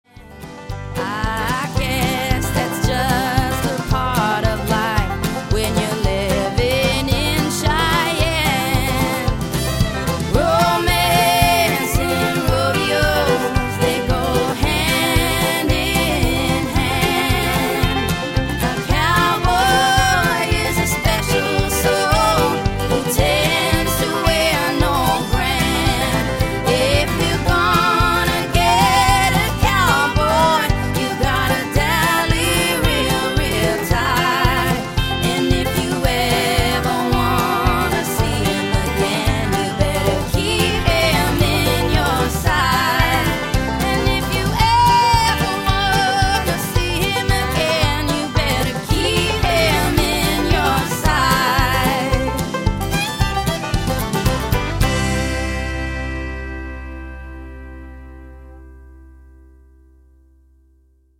Let’s pick it up going into the final chorus:
Banjo Online